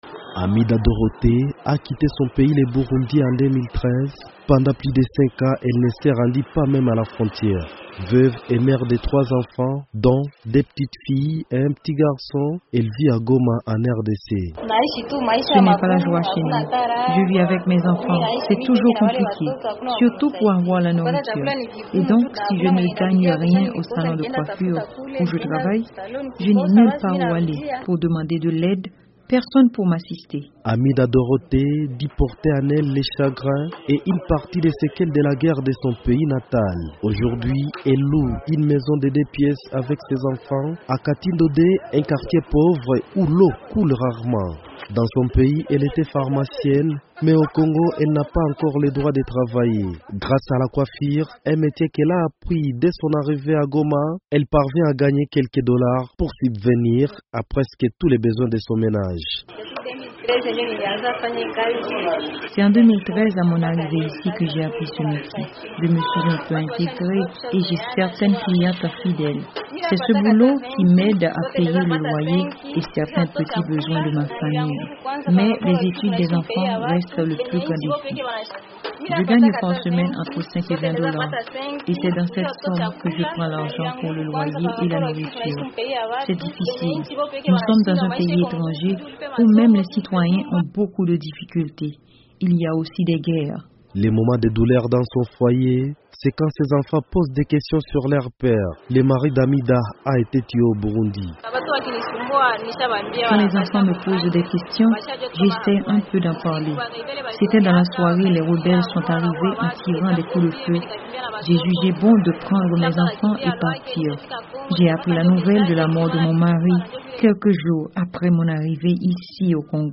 Au Nord-Kivu dans l’est de la RDC , le Haut Commissariat des Refugiés a enregistré plus de 200 000 réfugiés Rwandais, Sud-soudanais ainsi que des Burundais qui ont fui la dernière crise électorale de leur pays. Reportage sur l'intégration des ces régugiés à l’occasion de la Journée Internationale des Réfugiés.